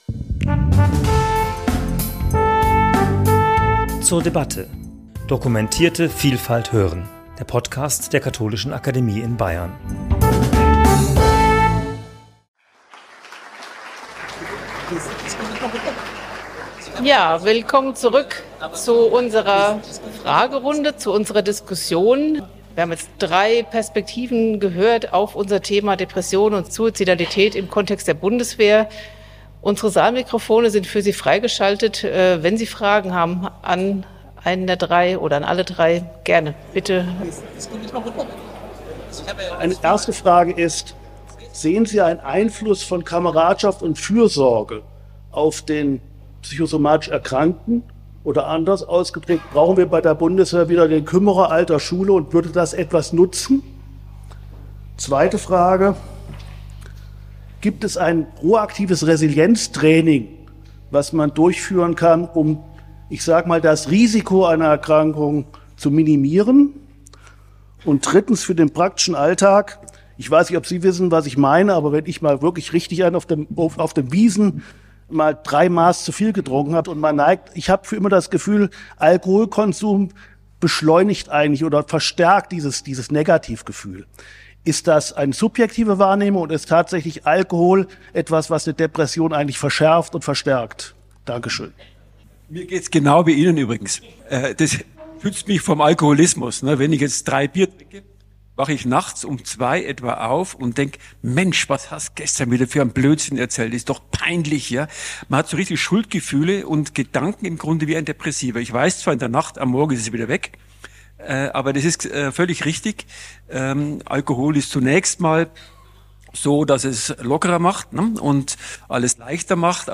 Gespräch zum Thema 'Suizidalität im Kontext der Bundeswehr' ~ zur debatte Podcast
Das Gespräch zum Thema 'Suizidalität im Kontext der Bundeswehr' fand am 8.10.2024 in der Katholischen Akademie in Bayern statt.